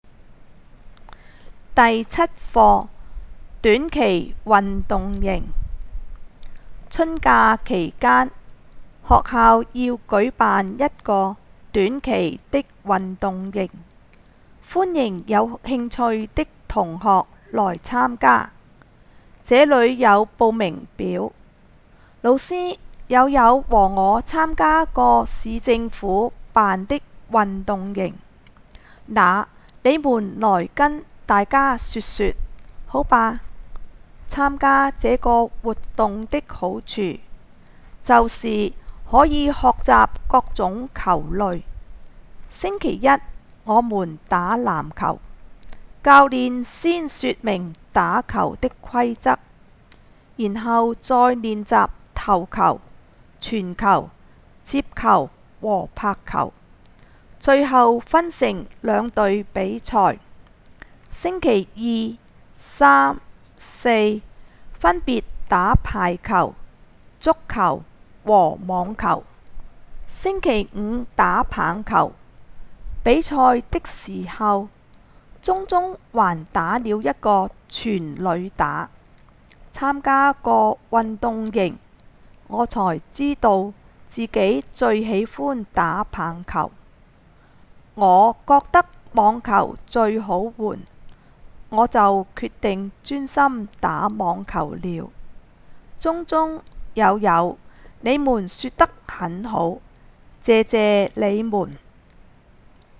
課文錄音